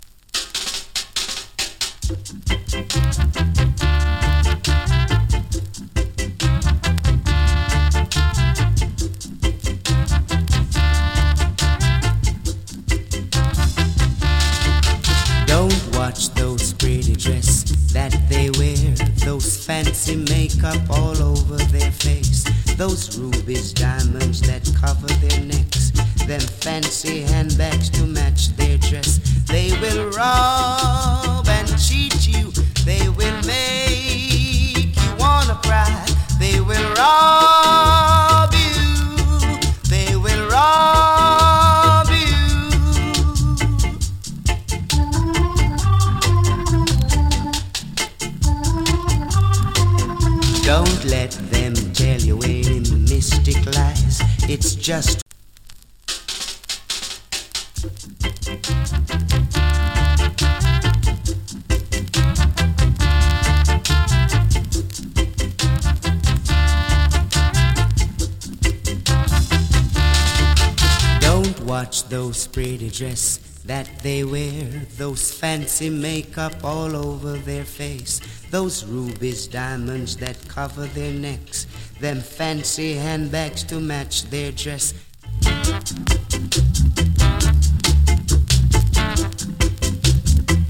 わずかにノイズ有り。
COMMENT 71年 NICE VOCAL EARLY REGGAE ! 90年代頃の再発盤かと思われます。